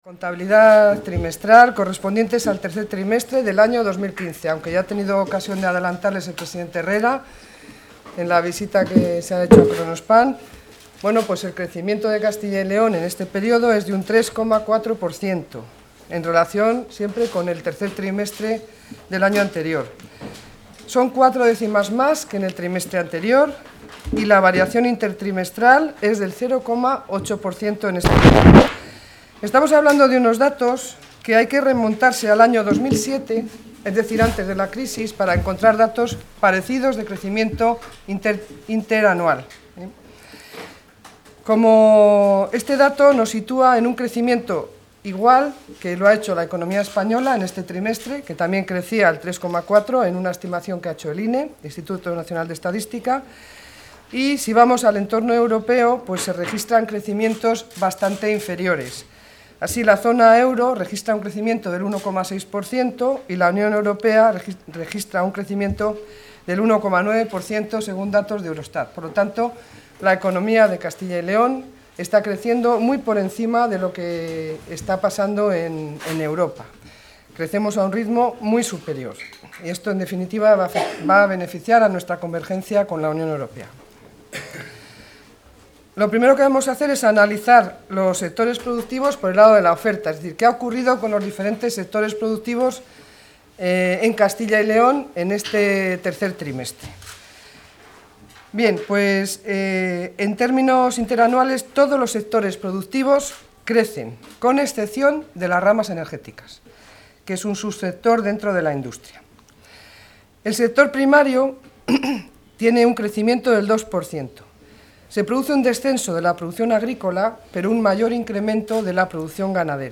Audio consejera de Economía y Hacienda.